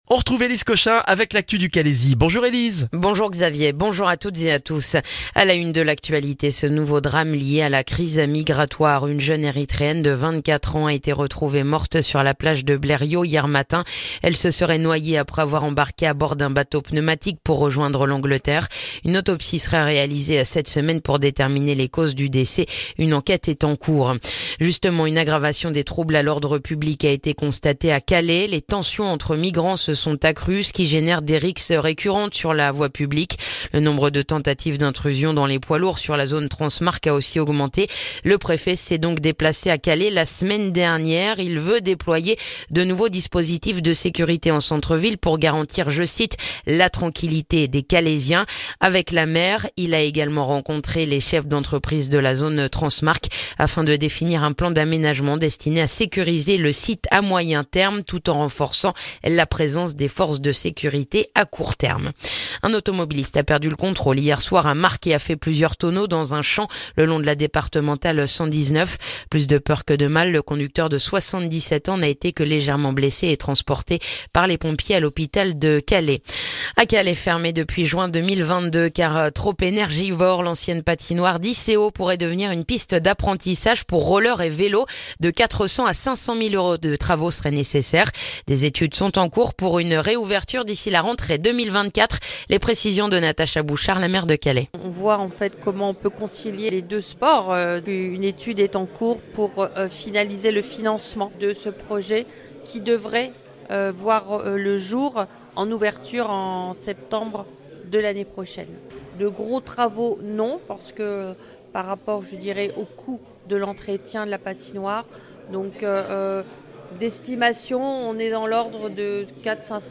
Le journal du mercredi 27 septembre dans le calaisis